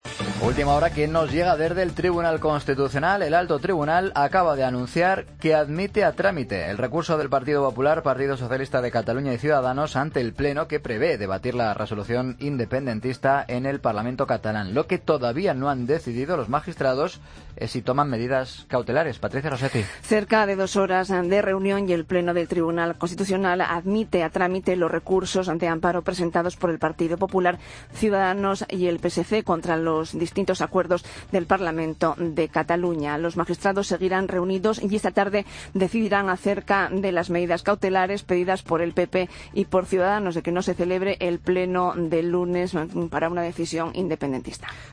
AUDIO: El Tribunal Constitucional admite los recursos de amparo. Crónica